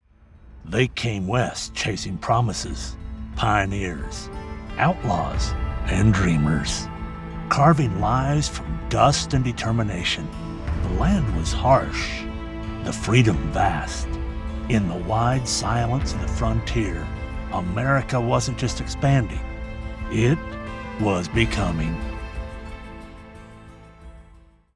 Adult (30-50) | Older Sound (50+)
0722History_Documentory.mp3